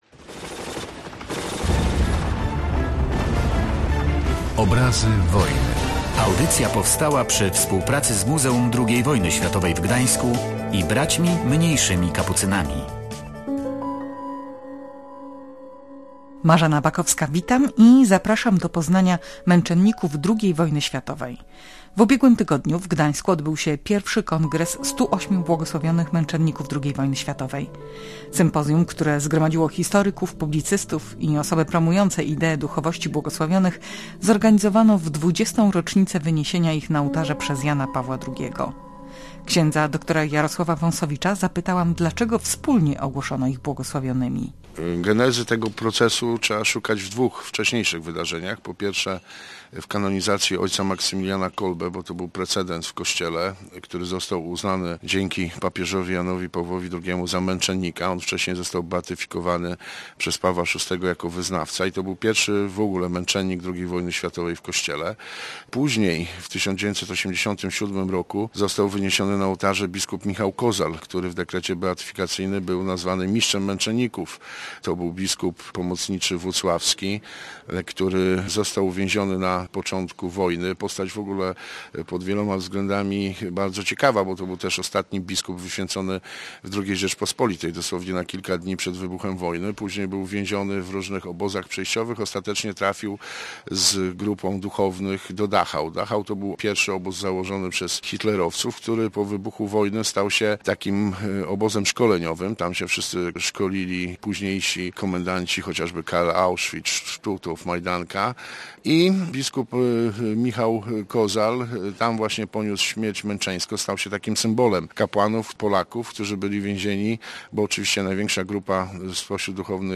Przybliżane są niektóre postacie z panteonu wyniesionych na ołtarze w 1999 roku przez Jana Pawła II: ks. Franciszek Drzewiecki, ks. Józef Kowalski i s. Alicja Kotowska. Opowiadają o nich uczestnicy sympozjum